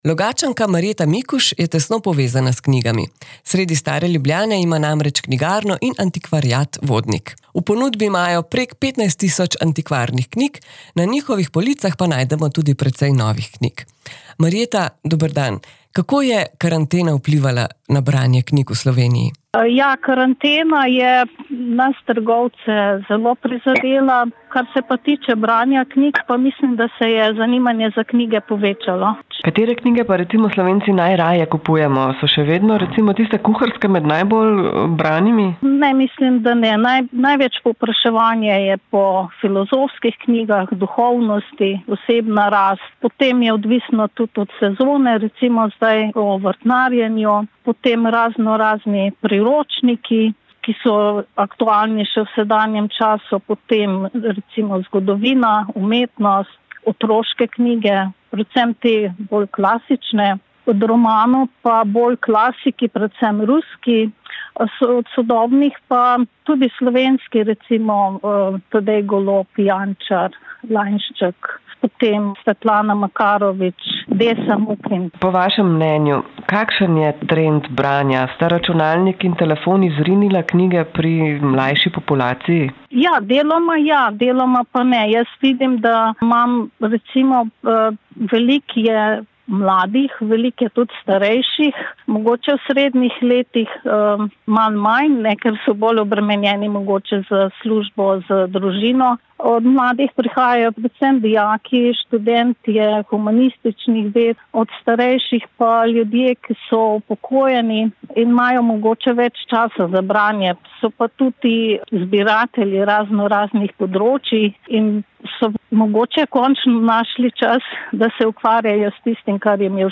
• NEDELJSKI KLEPET